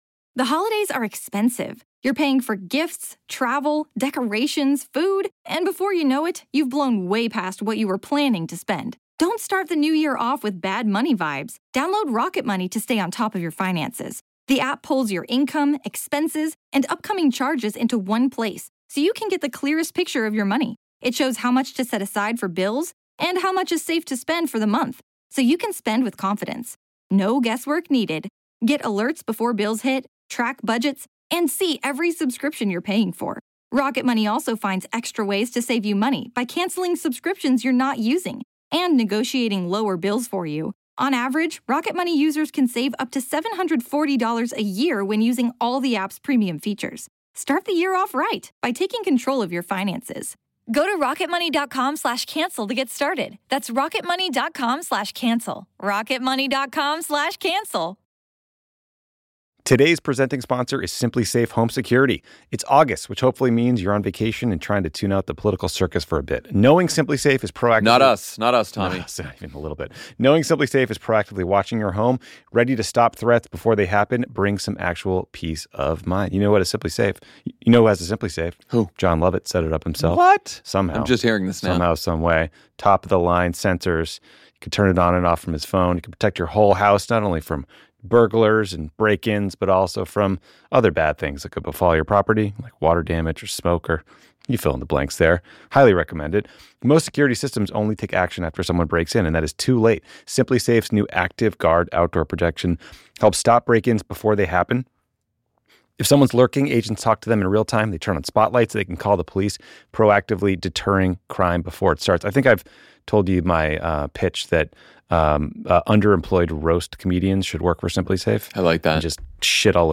Texas Democrats, in an attempt to block Trump's redistricting effort, shut down a special legislative session by fleeing the state. Texas State Rep. James Talarico joins the show to explain what happens now and why he and his Democratic colleagues believe that getting out of town is the best way to serve their constituents in this moment.